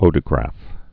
(ōdə-grăf)